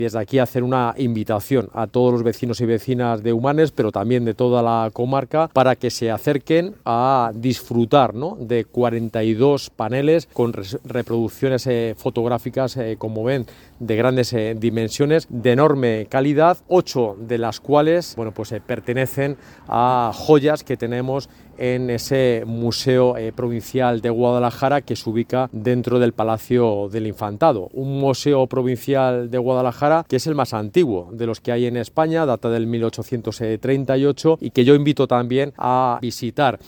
José Luis Escudero, delegado de la Junta en Guadalajara, ha asegurado que “hago una invitación a todos los vecinos y vecinas de Humanes, pero también de toda la comarca para que se acerquen a disfrutar de 42 paneles con reproducciones fotográficas como ven de grandes dimensiones de enorme calidad, ocho de las cuales pertenecen a joyas que tenemos en ese Museo Provincial de Guadalajara que se ubica dentro del Palacio del Infantado.